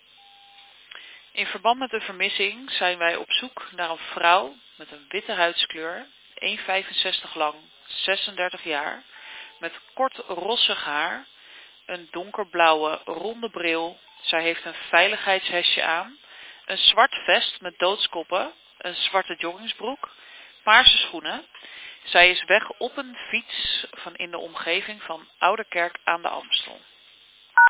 Burgernet heeft een audioboodschap ingesproken bij deze melding.